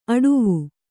♪ aḍuvu